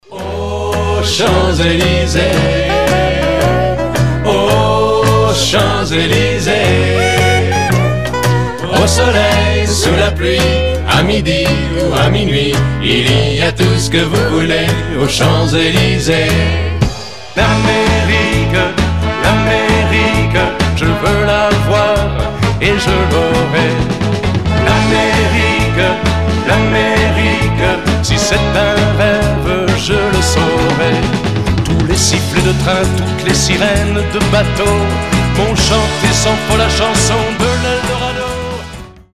• Качество: 320, Stereo
французские
Mashup